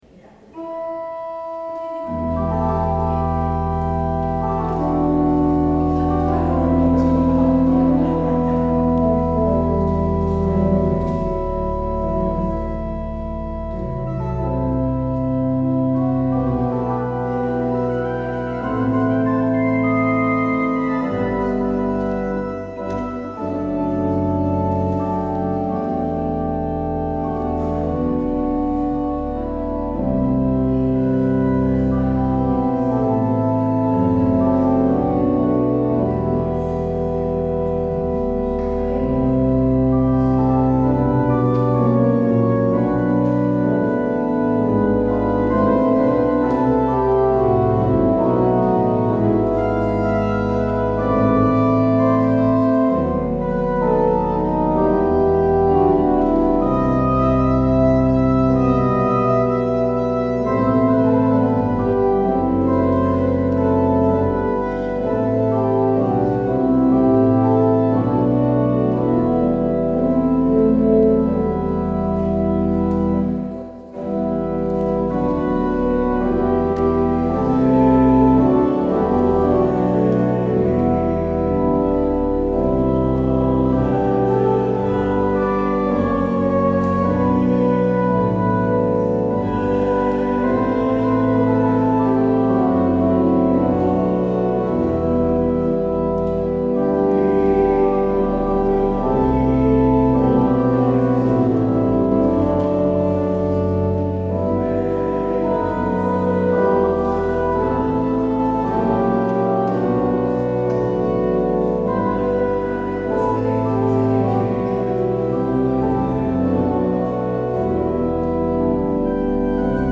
 Luister deze kerkdienst hier terug: Alle-Dag-Kerk 1 oktober 2024 Alle-Dag-Kerk https
Het openingslied is: NLB 146: 1, 4 en 5.
Het slotlied is: NLB 838: 1 en 2.